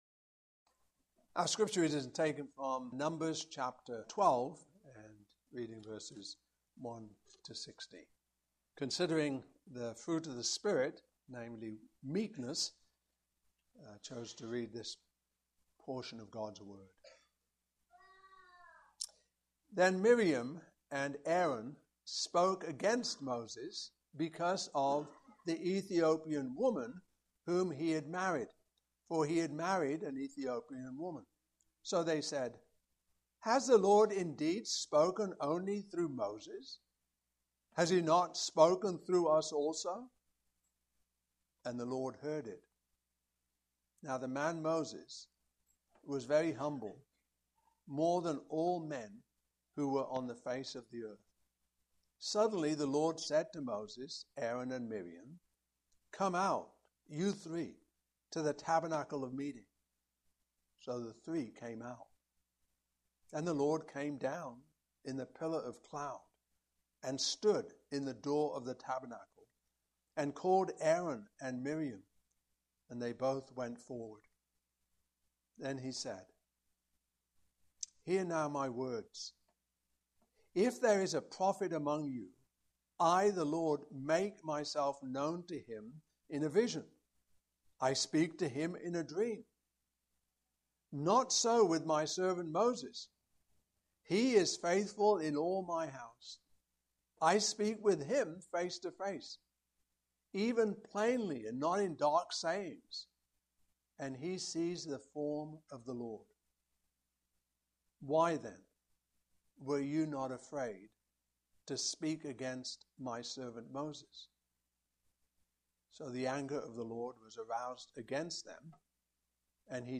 Fruit of the Spirit Passage: Numbers 12:1-16 Service Type: Morning Service Topics